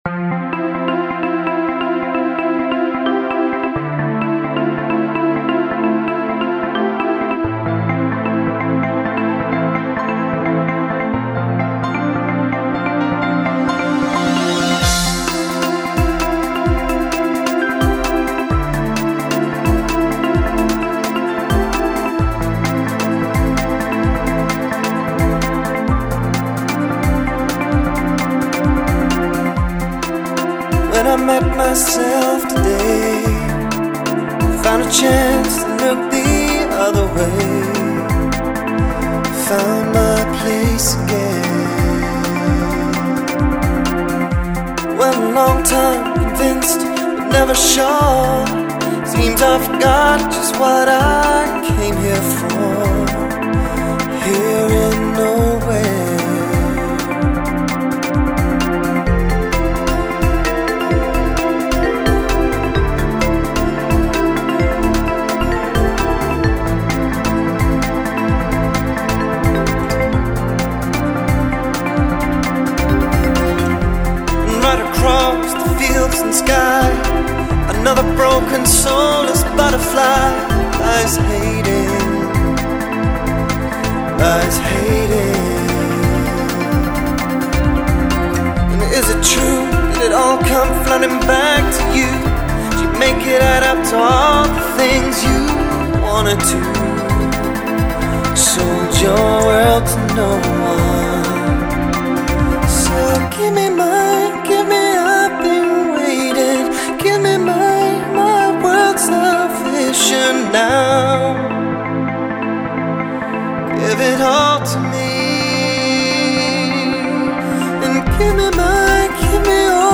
& Boogie    Pop, Rock & most
Trumpet   Guitar